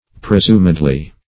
presumedly - definition of presumedly - synonyms, pronunciation, spelling from Free Dictionary Search Result for " presumedly" : The Collaborative International Dictionary of English v.0.48: Presumedly \Pre*sum"ed*ly\, adv.
presumedly.mp3